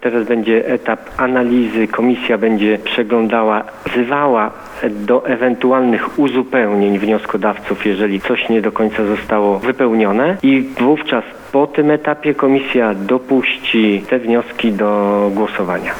– Dopiero po weryfikacji, komisja zdecyduje, które wnioski spełniają wszystkie wymogi formalne i rozpocznie się głosowanie – dodaje Robert Betyna.